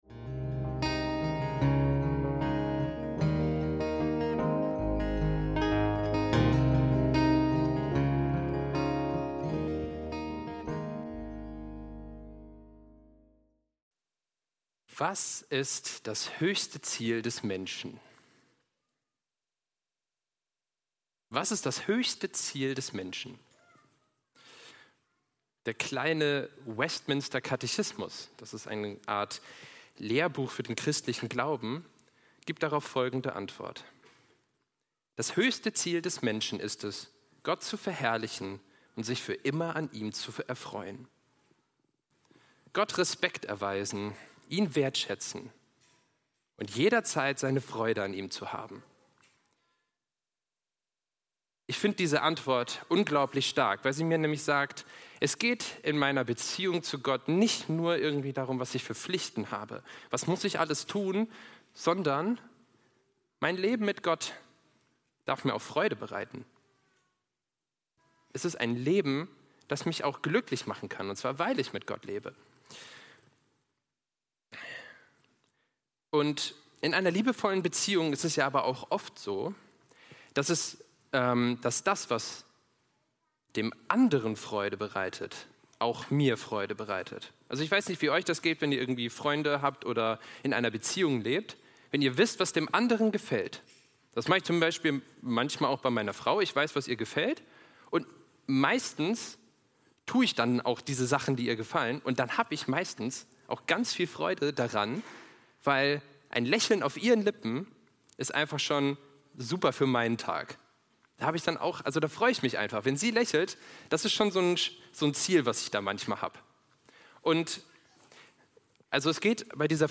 - Predigt vom 27.04.25